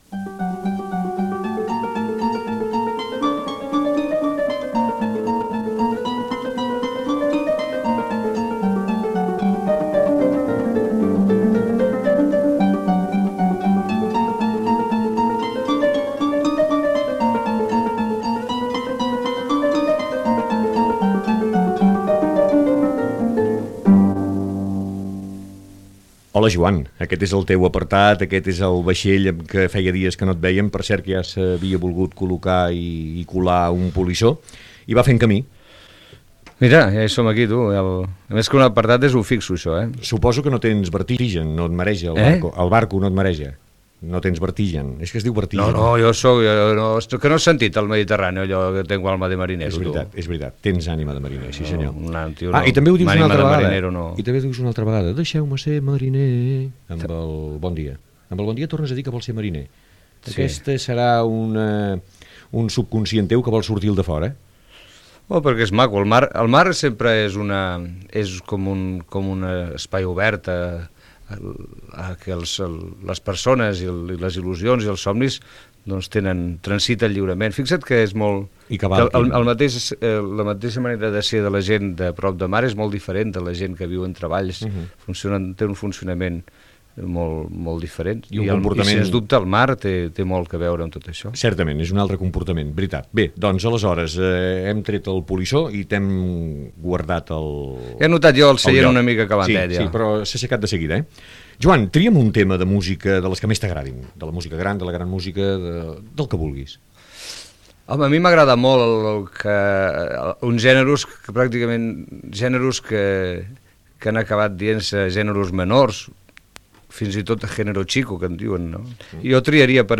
Conversa amb el cantant Joan Manuel Serrat. La seva opinió sobre Pilar Miró, nomenada directora general de RTVE.
Entreteniment
FM